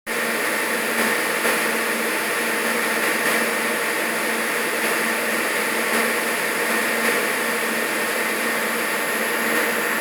Wir haben den Vorher-Nachher-Vergleich für Sie aufgenommen:
Grafik: Serverschrank FrequenzbereichsmessungWir hatten ja schon auf unserer Facebookseite von der groben Frequenzbereichmessung berichtet, die wir kürzlich an unserem Serverschrank vorgenommen haben – daran sieht man, dass typische Server überwiegend in dem Bereich zwischen 200 (Stufe 1) bzw. 500 (Stufe 2) und 9000 Hertz Emissionen aussenden.
ServerschrankgeräuschVorher.m4a